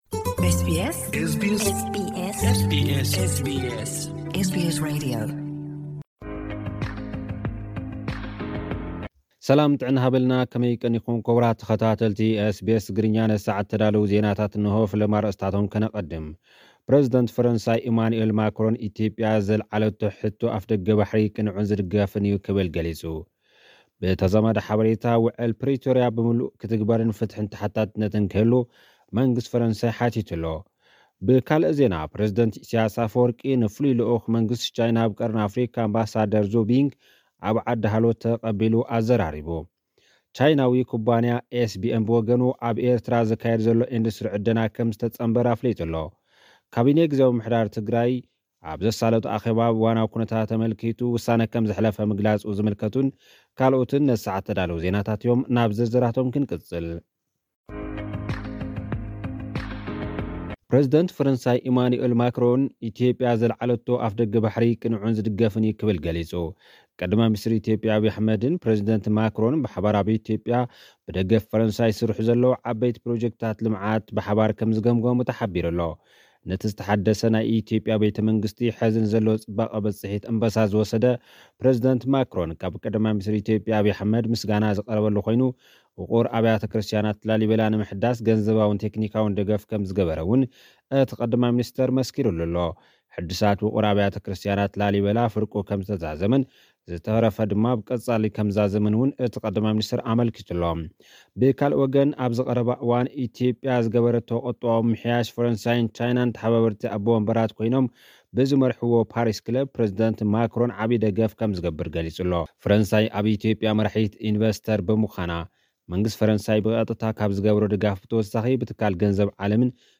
ፕረዚደንት ፈረንሳ ኣብ ሕቶ ኣፍደገ ባሕሪ ኢትዮጵያ ርእይቶ ሂቡ (ጸብጻባት ዜና 23 ታሕሳስ 2024)